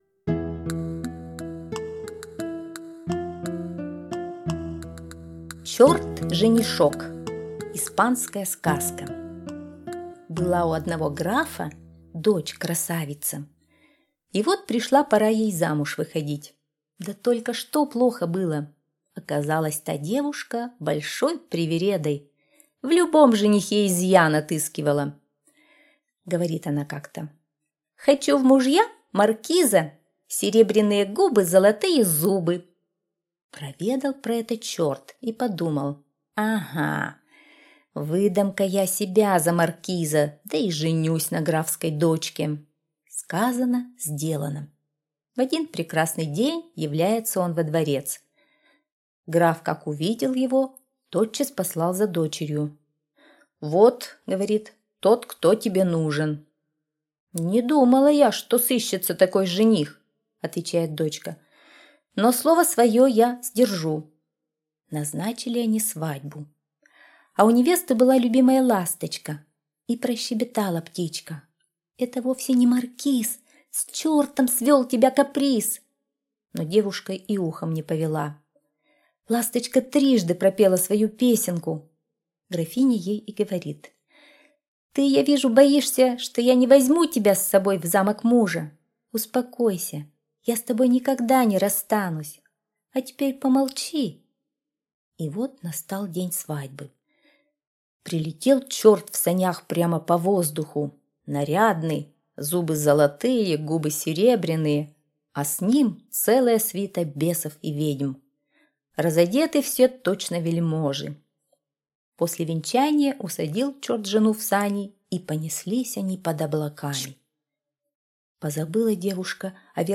Чёрт-женишок - испанская аудиосказка - слушать